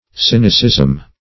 Search Result for " sinicism" : The Collaborative International Dictionary of English v.0.48: Sinicism \Sin"i*cism\ (s[i^]n"[i^]*s[i^]z'm), n. (Ethnol.)
sinicism.mp3